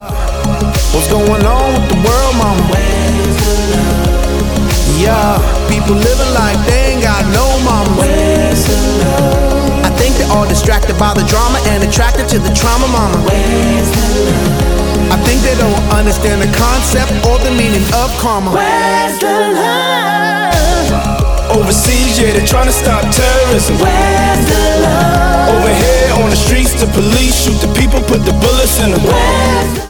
• Pop
hip hop group